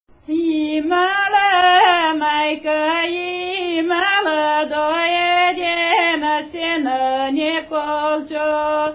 музикална класификация Песен
форма Двуредична
размер Две четвърти
фактура Едногласна
начин на изпълнение Солово изпълнение на песен
битова функция На хоро
фолклорна област Югоизточна България (Източна Тракия с Подбалкана и Средна гора)
начин на записване Магнетофонна лента